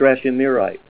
Say STRASHIMIRITE